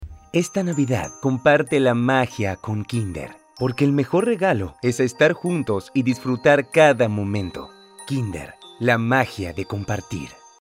Distintas tonalidades y estilos de voz
Español Neutro
Cálido / Relajado
calido-relajado.mp3